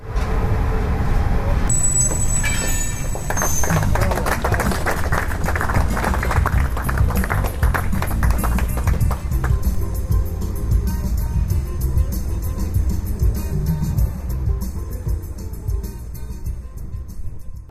Voz ulazi u stanicu